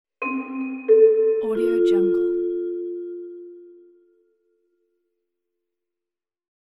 دانلود افکت صدای اطلاعیه فرودگاه
دانلود افکت صوتی شهری
افکت صدای اطلاعیه فرودگاه یک گزینه عالی برای هر پروژه ای است که به صدا و جنبه های دیگر مانند فرودگاه، هشدار و اعلان نیاز دارد.
Sample rate 16-Bit Stereo, 44.1 kHz
Looped No